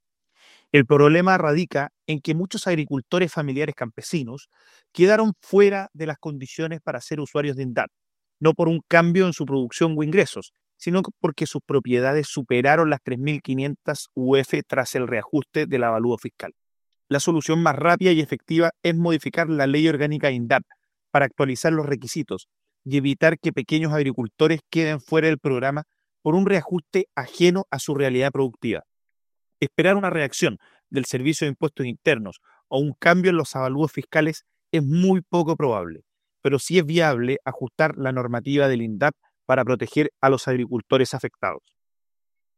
Cuña-avaluo-fiscal.mp3